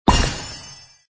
baozha.mp3